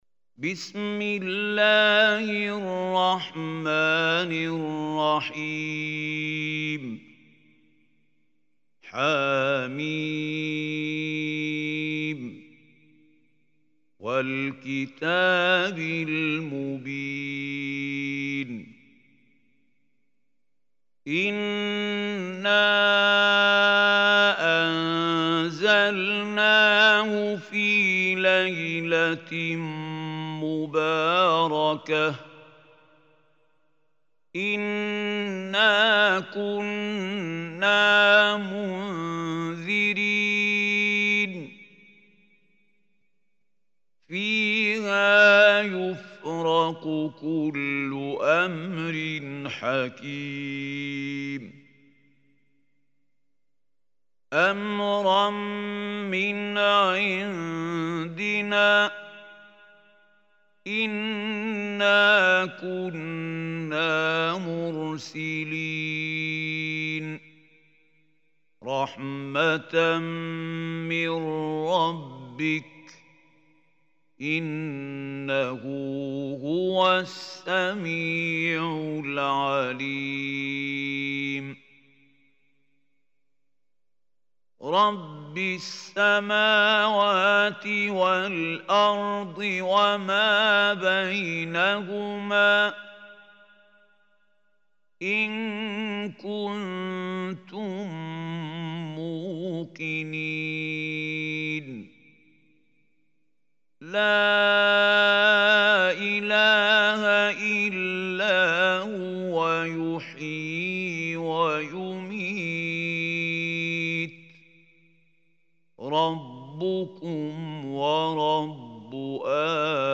Surah Dukhan MP3 Recitation by Mahmoud Khalil